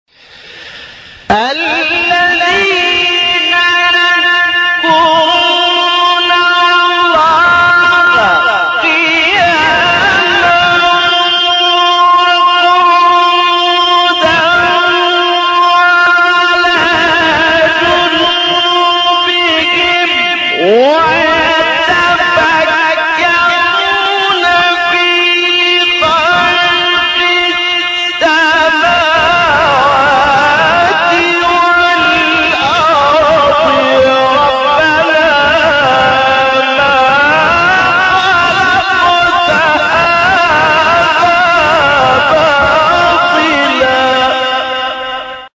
آیه 191 سوره آل عمران استاد محمد لیثی | نغمات قرآن | دانلود تلاوت قرآن